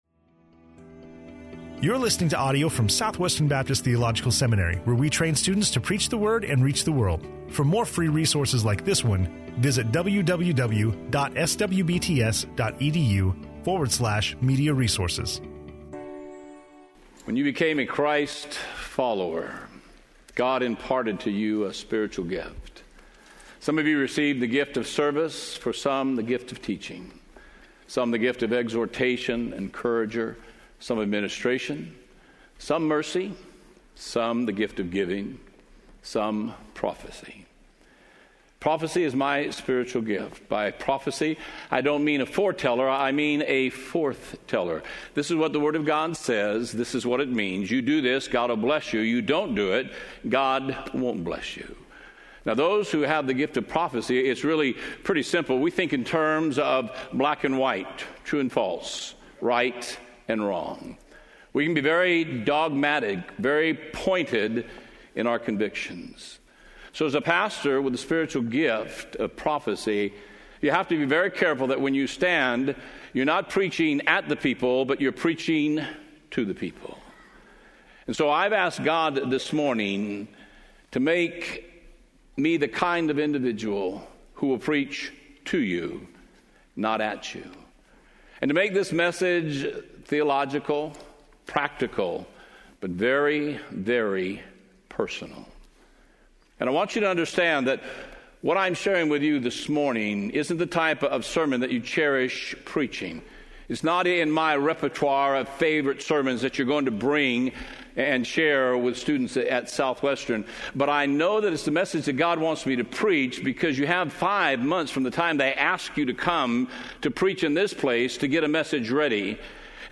SWBTS Chapel